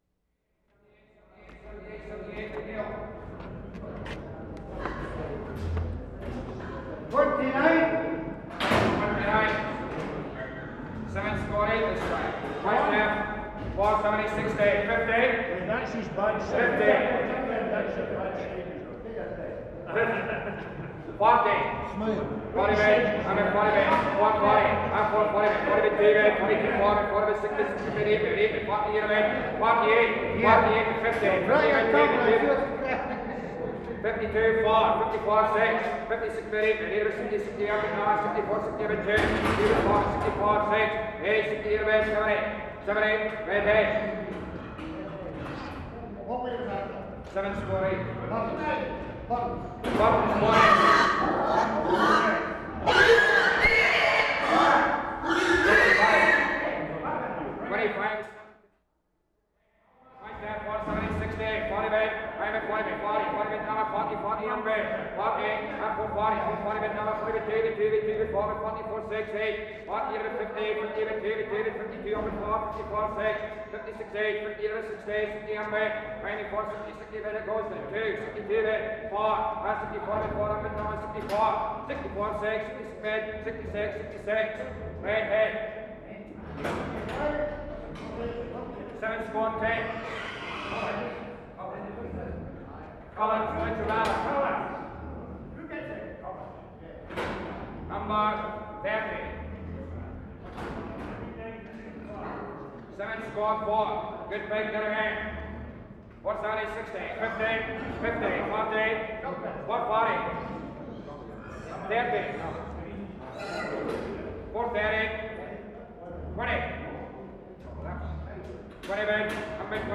WORLD SOUNDSCAPE PROJECT TAPE LIBRARY
Stirling / Dollar / Preston, England May 29/75
1 - 4. AUCTIONS AT STIRLING MARKET
mark * pigs
Note: different callers for each ring.
4. There is more of an obvious exchange between caller and buyers, possibly because there are fewer people in this room. Pigs scream fearfully in intervals.